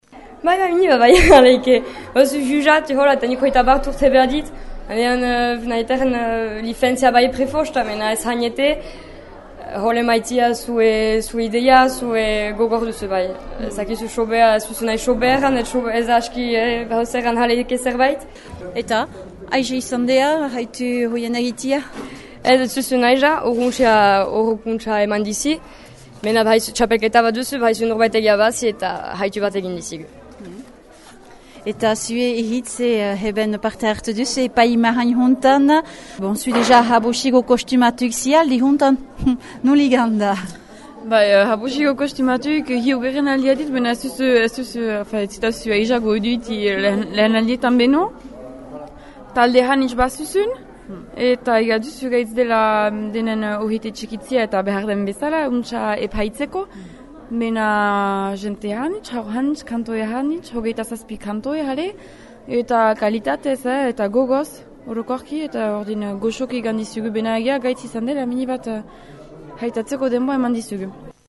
Entzün Kantu txapelketako epai mahaina  :